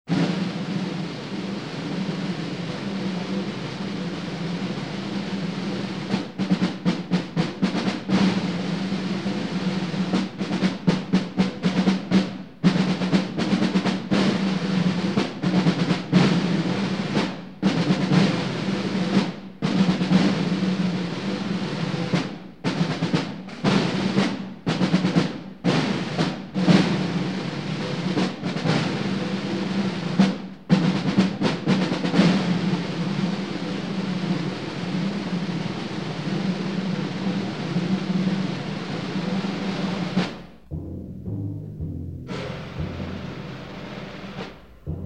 The entire CD is in stereo